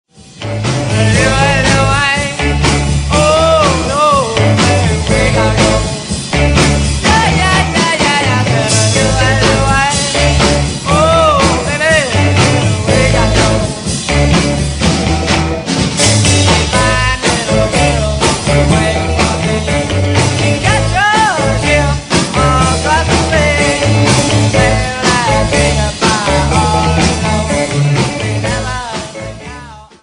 Oldies